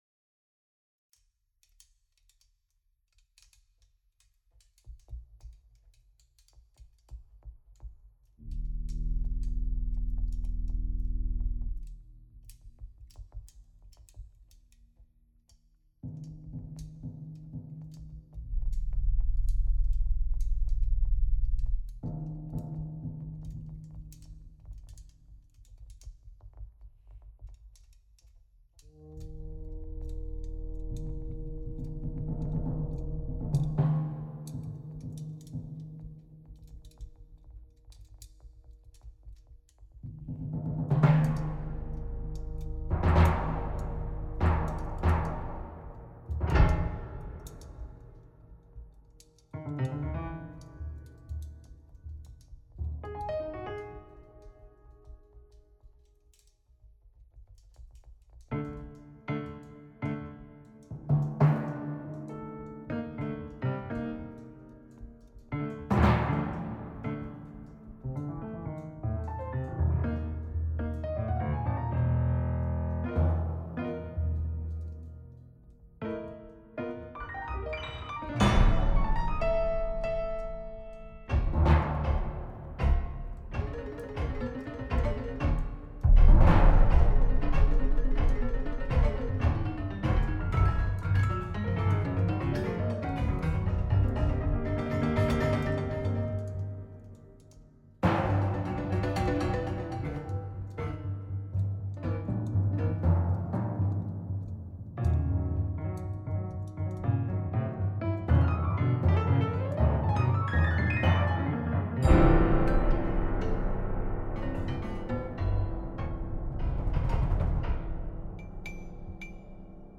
Piano naturel ou dénaturé à la convenance du musicien. Notes tenues avec bruits de pistons et de clefs d’un bugle . Sons de Contrebasse jouée à l’archet. Timbales – Grosse caisse classique – Enclumes – Waterphone . Drone avec effet Doppler.